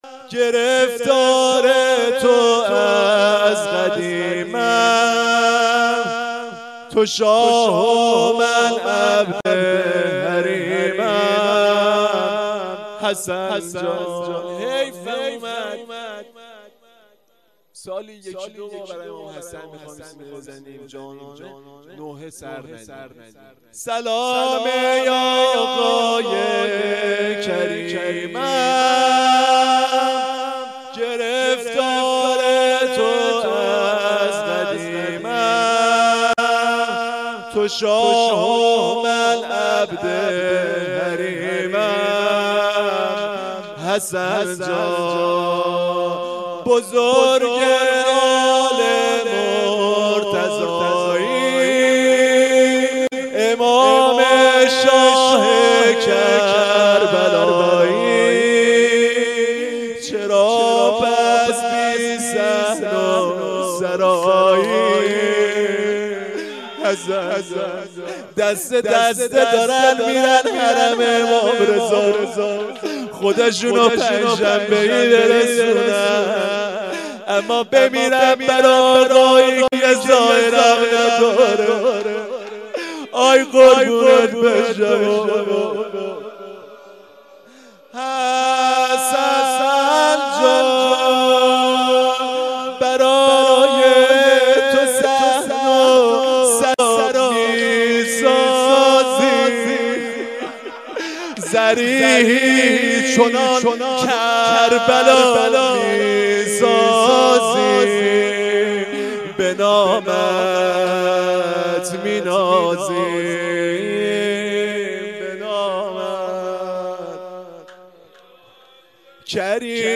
4-nohe.mp3